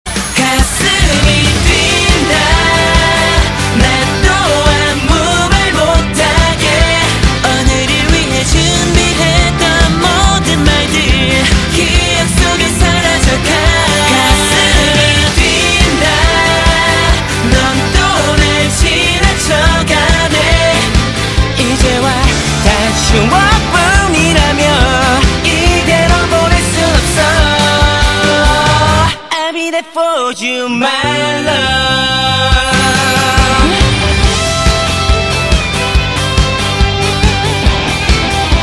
M4R铃声, MP3铃声, 日韩歌曲 156 首发日期：2018-05-15 13:14 星期二